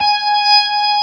55o-org19-G#5.wav